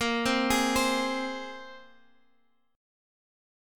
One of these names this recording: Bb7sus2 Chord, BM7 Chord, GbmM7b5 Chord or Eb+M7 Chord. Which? Bb7sus2 Chord